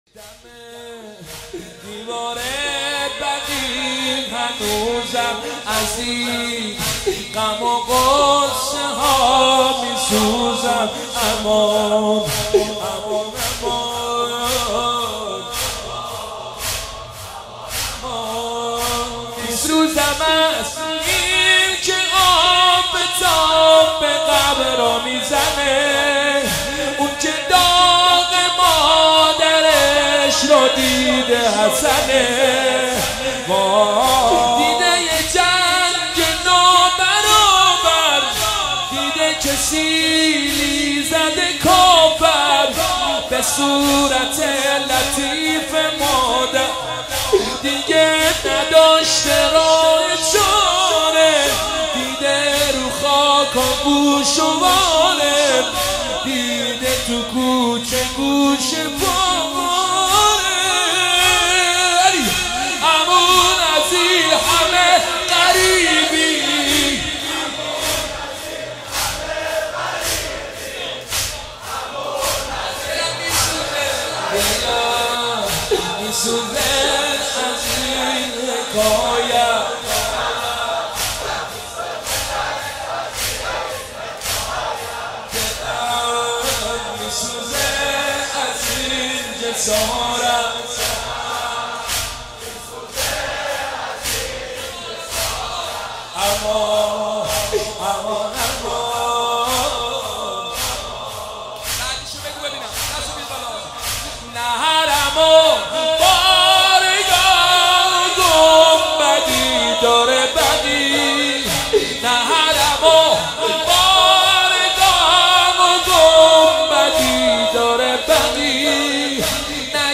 روز جهانی تخریب بقیع هیئت معظم کربلا کرمان22 خرداد 98